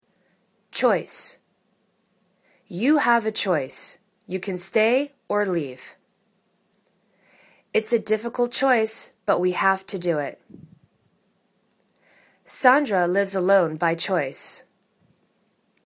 choice  /chois/ [C] [U]